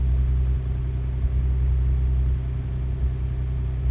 rr3-assets/files/.depot/audio/Vehicles/ttv6_02/ttv6_02_idle.wav
ttv6_02_idle.wav